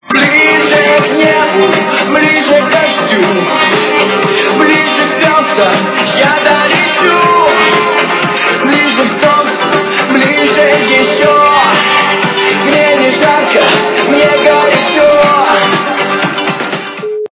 русская эстрада
При заказе вы получаете реалтон без искажений.